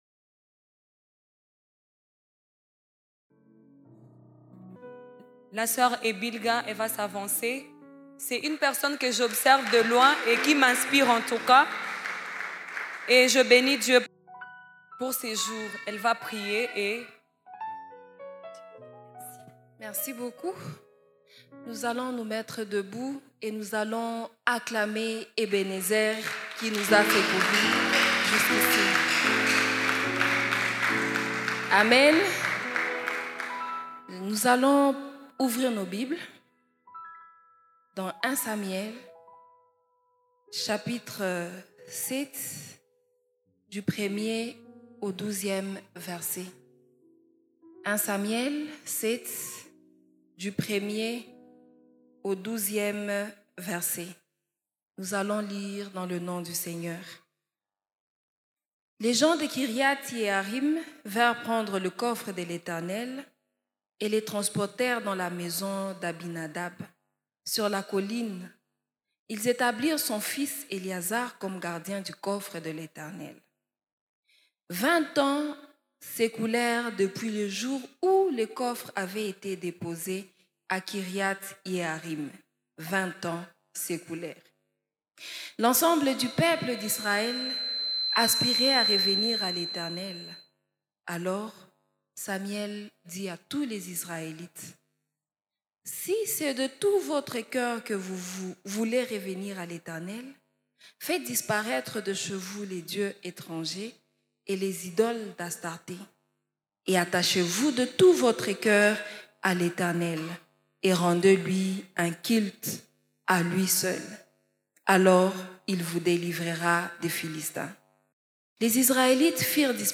Predications